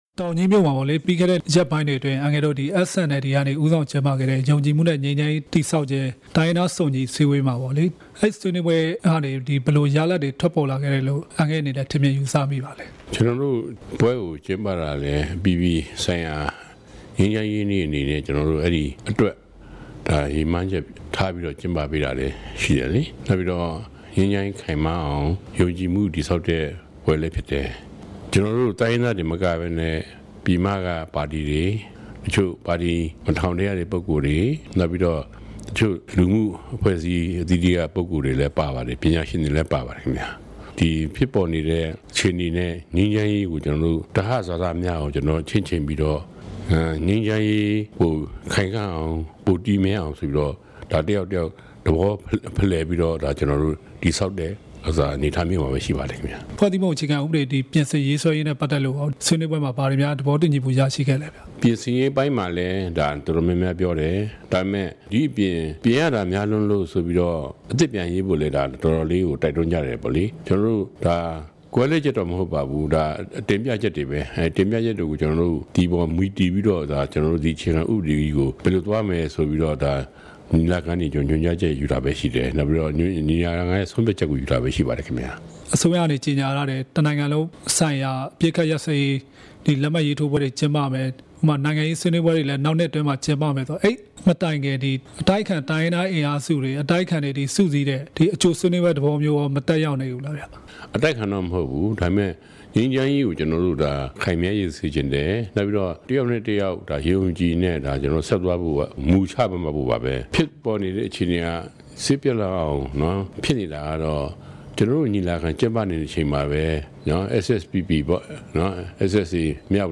တောင်ကြီး တိုင်းရင်သား ညီလာခံအကြောင်း ဦးခွန်ထွန်းဦးနဲ့ တွေ့ဆုံမေးမြန်းချက်